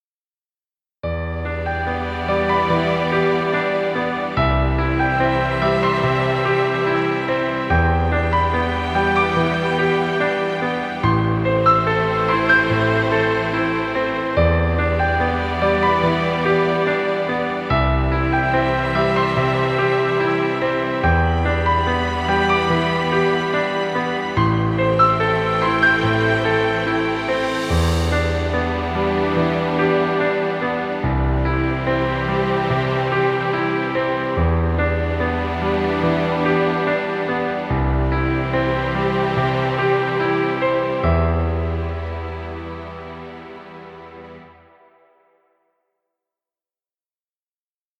Romantic cinematic music. Background music Royalty Free.